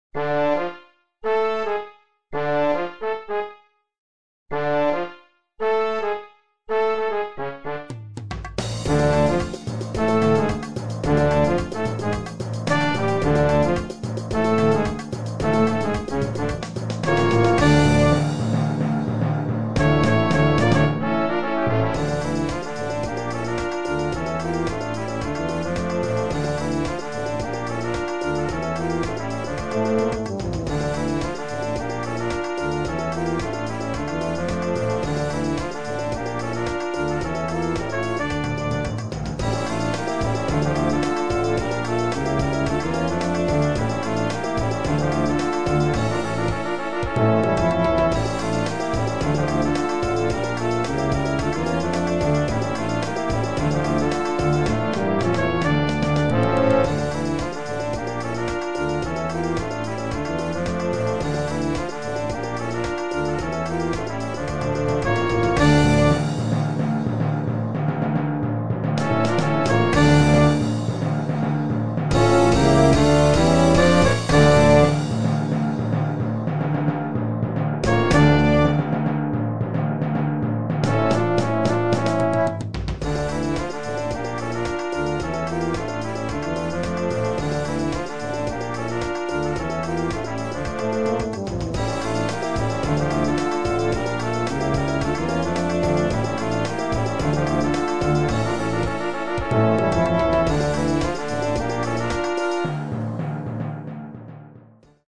Solo für zwei Pauken & Young Band.
Noten für flexibles Ensemble, 4-stimmig + Percussion.